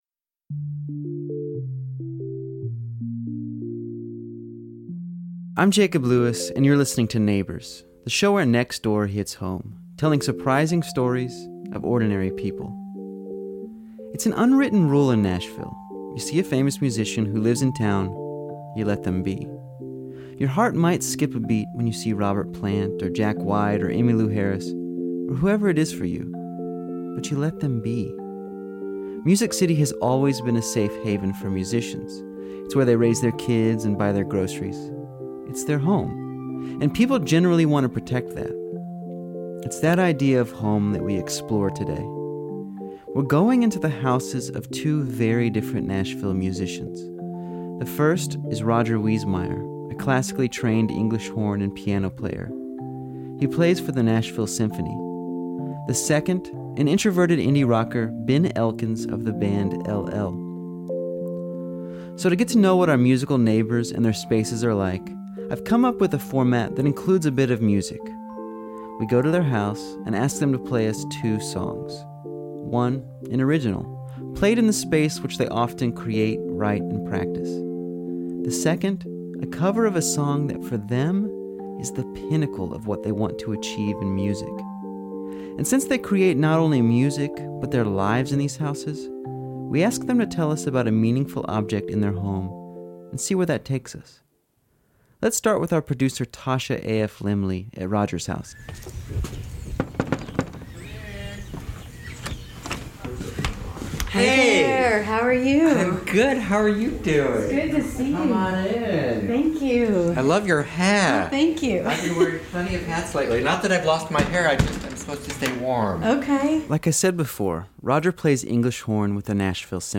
To get to know what our musical neighbors and their spaces are like we have come up with a format that includes a bit of music.